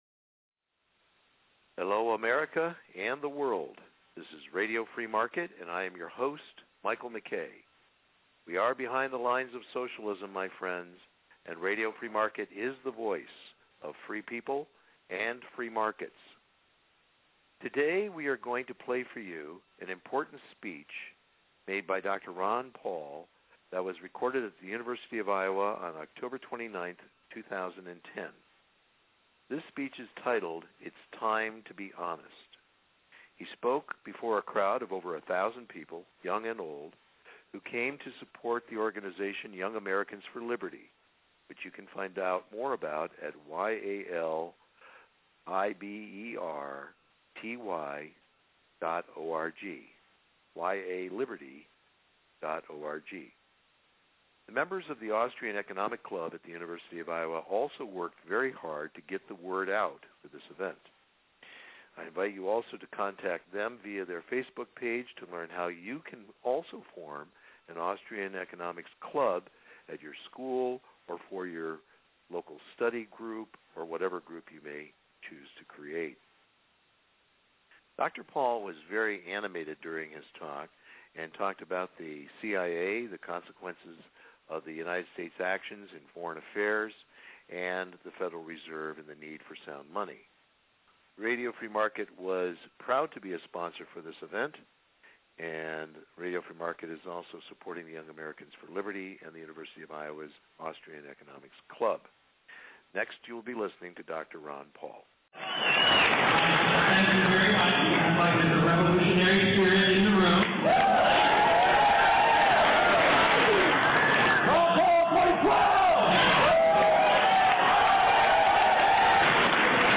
[Due to technical difficulties the sound quality of this broadcast is below our normal standard.
While this archive file is challenging to listen to it is well worth the effort.
On October 29, 2010 Dr. Ron Paul spoke to over 1,000 people at the University of Iowa.